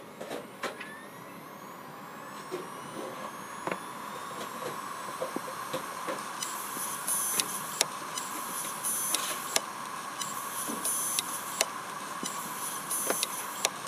続いて、「シャーーーー」「カンカンカン」「ジーコジーコ」に代表される異音がする場合は、物理障害です。
「ジーコジーコ」のサンプル音
HDD-Errror-ji-ko.mp3